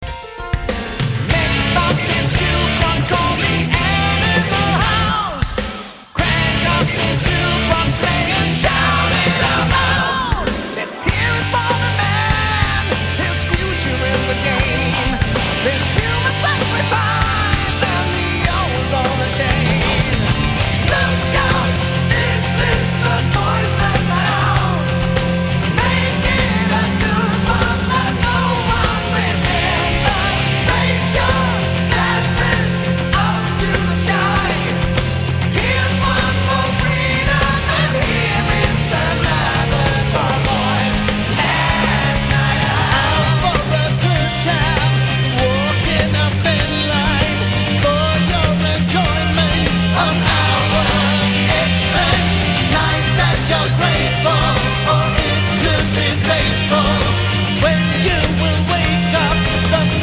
massive big background vocals.
keyboards, bass, guitars
lead and background vocals
drums
acoustic guitar
guitar solos